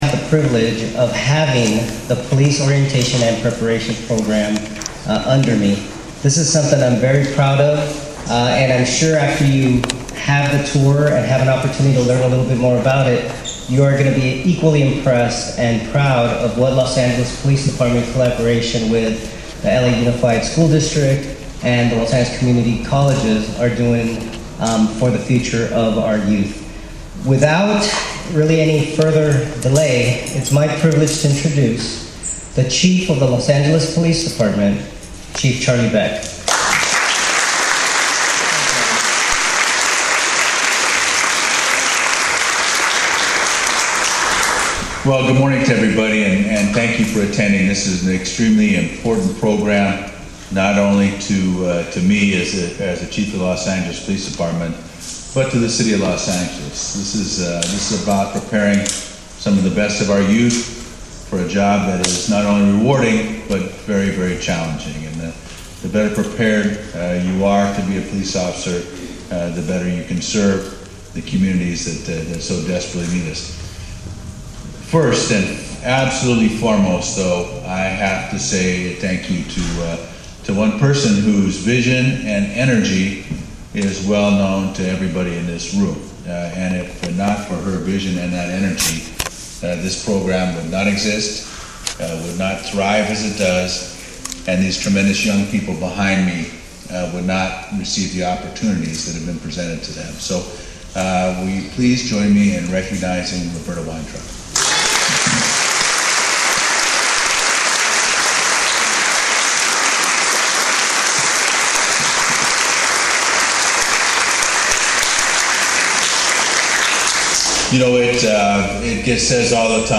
On Thursday December 6, 2024 Chief of Police Charlie Beck held his monthly media availability to discuss several issues with members of the press. Chief Beck provided crime statistics and discussed the expansion of the Department’s Lock it, Hide it, Keep it campaign developed to address property crime in the City.